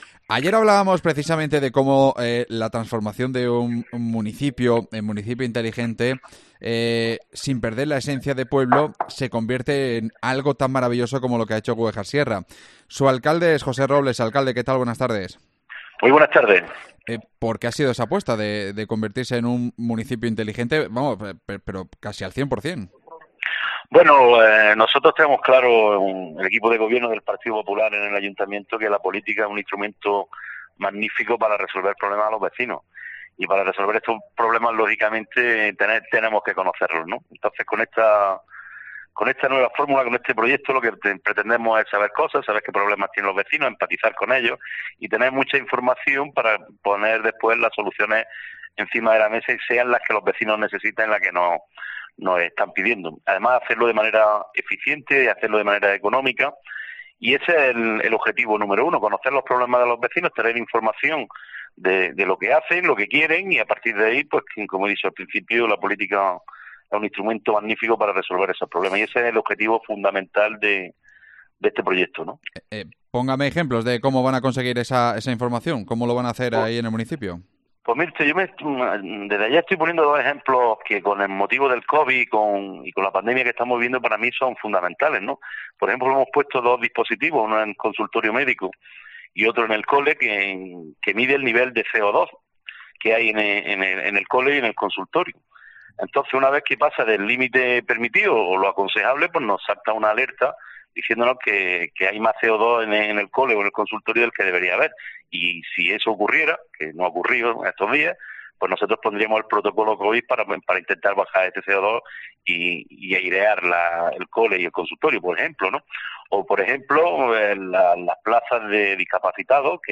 AUDIO: José Robles, su alcalde, repasa toda la actualidad granadina